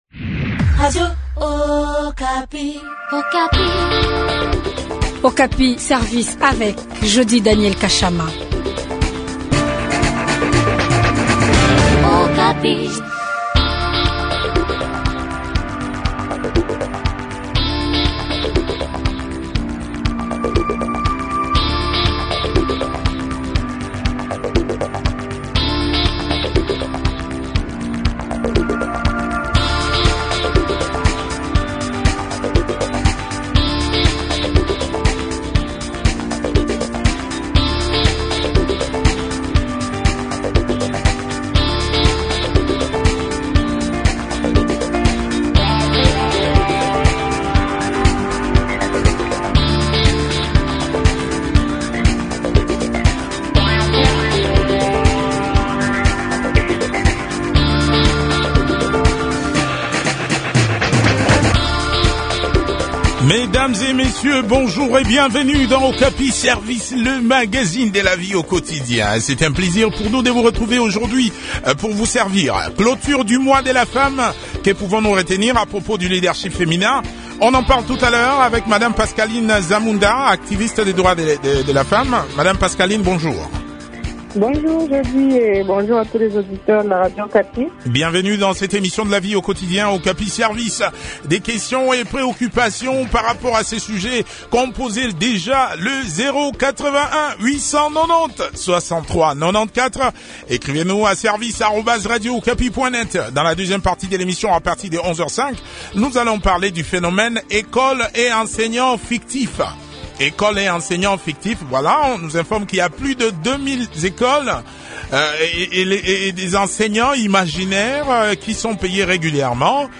a aussi participé à cet entretien.